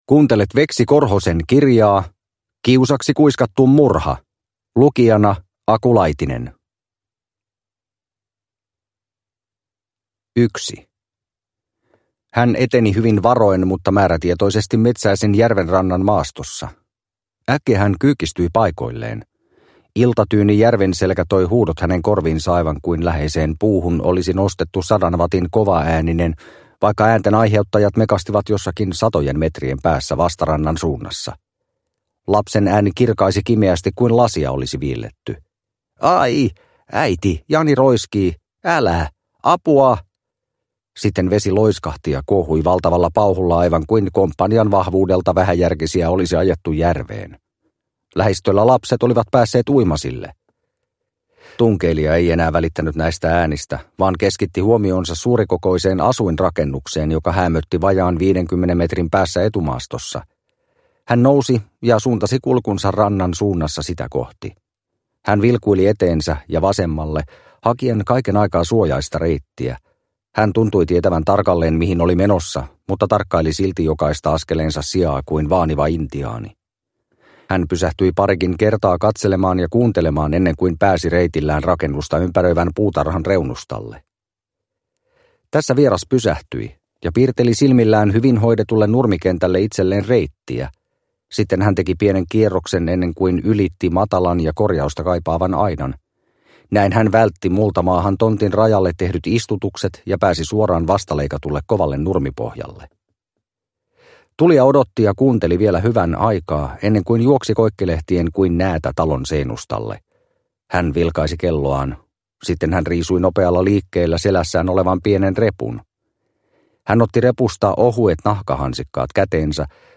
Kiusaksi kuiskattu murha – Ljudbok – Laddas ner